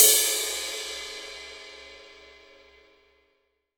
Index of /90_sSampleCDs/AKAI S-Series CD-ROM Sound Library VOL-3/16-17 CRASH